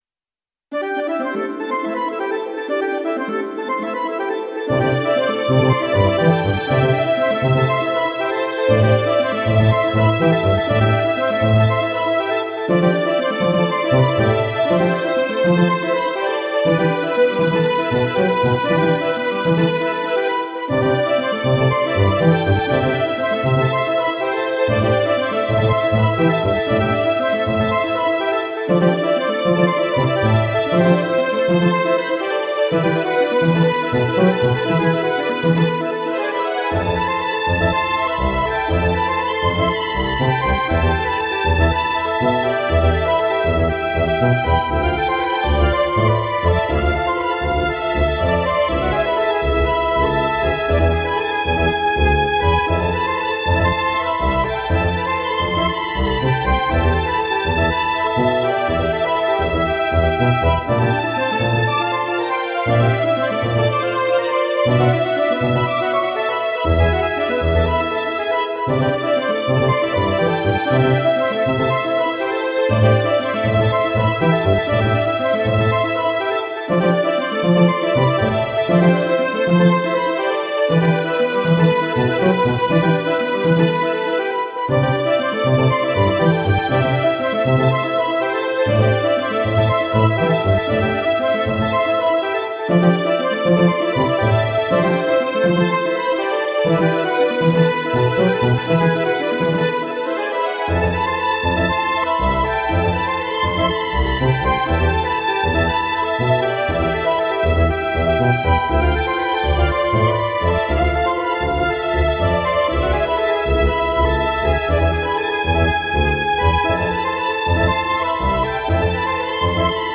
エレクトーンでゲーム音楽を弾きちらすコーナー。
使用機種：ELS-01C